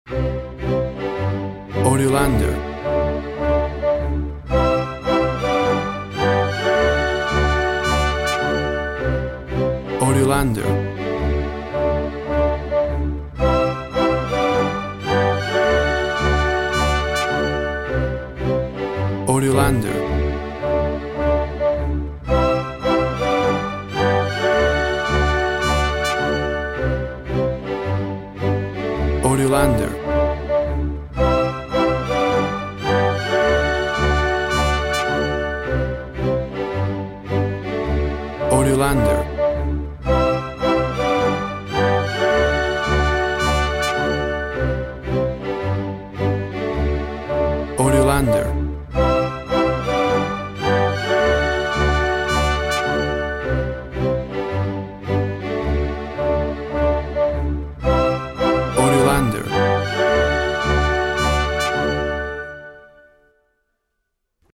WAV Sample Rate 16-Bit Stereo, 44.1 kHz
Tempo (BPM) 109